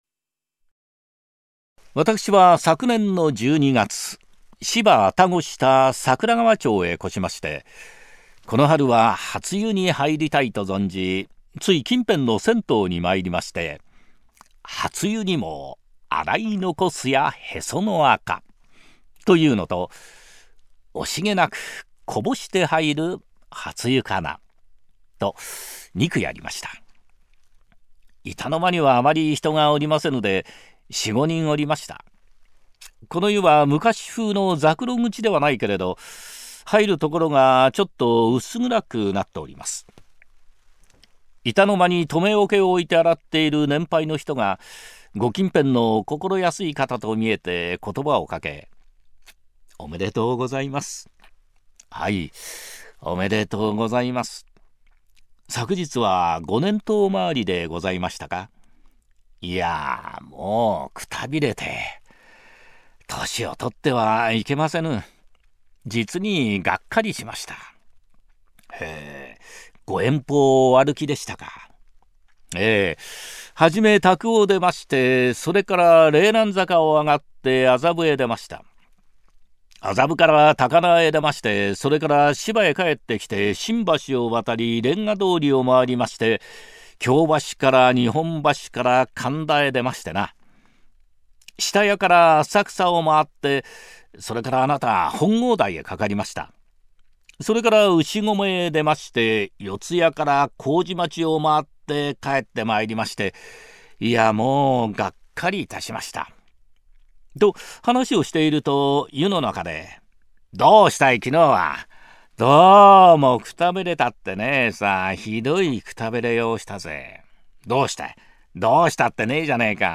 三遊亭円朝 朗 読